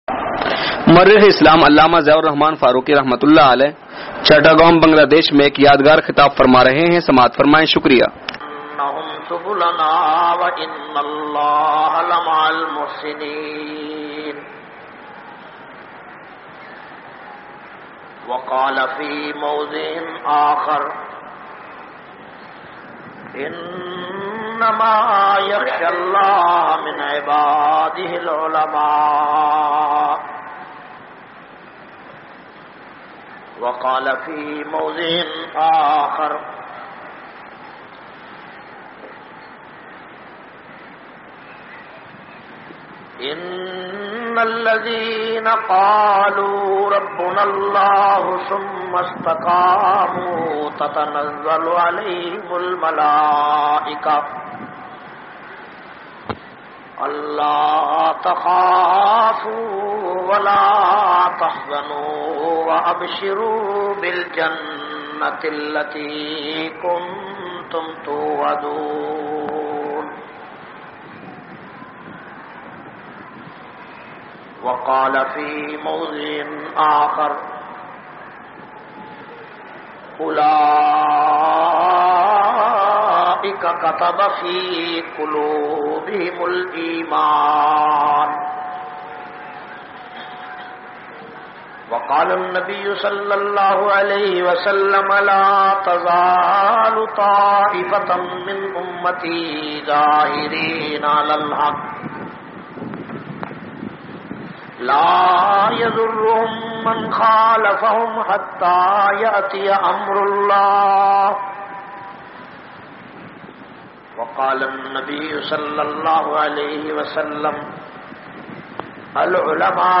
220- Ulma e deen ki qurbaniyan chittagang Bangladesh bayan.mp3